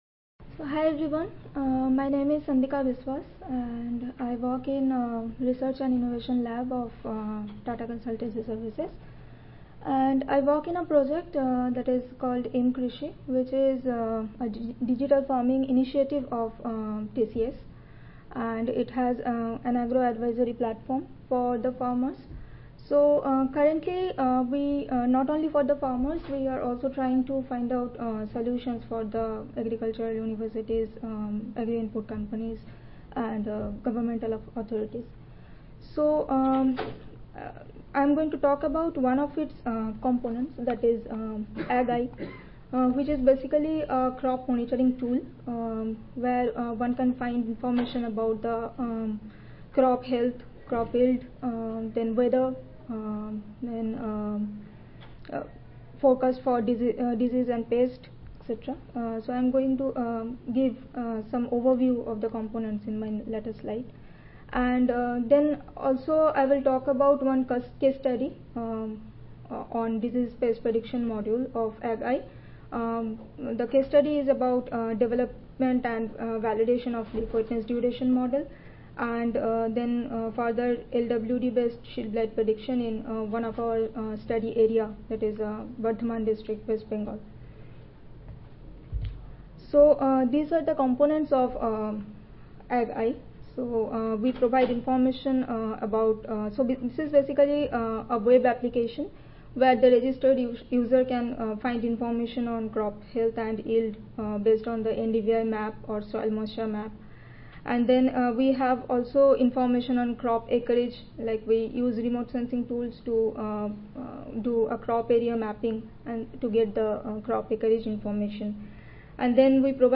See more from this Division: ASA Section: Climatology and Modeling See more from this Session: Examples of Model Applications in Field Research Oral